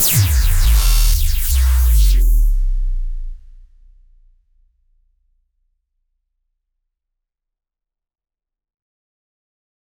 Royalty-free laser sound effects
Use electronic, futuristic sounds 0:10 som de baixo eletrônico molhado tipo um zap ou laser, hit, zap, laser shot 0:10 One shot Distorted upbeat futuristic house laser like synth supersaw 0:10
som-de-baixo-eletrnico-mo-m5snsuec.wav